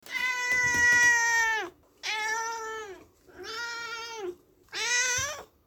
Download Cat Meowing sound effect for free.
Cat Meowing